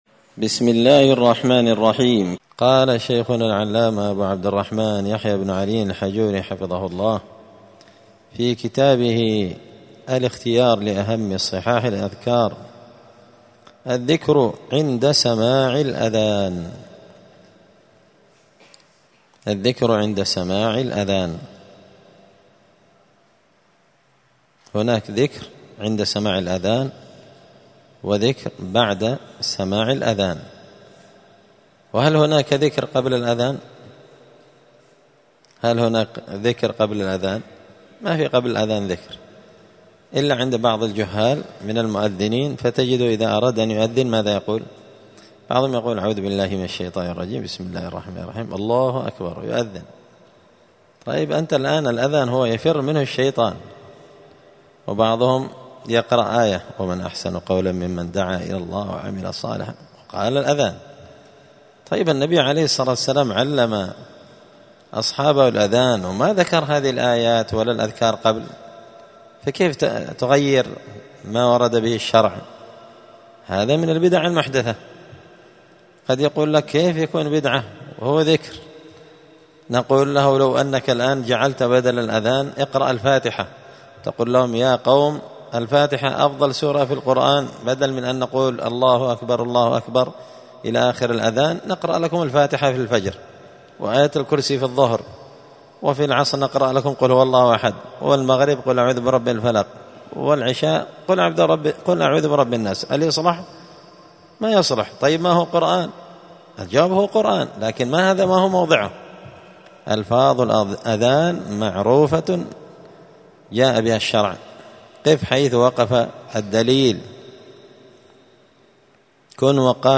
*{الدرس الرابع (4) الذكر عند سماع الأذان}*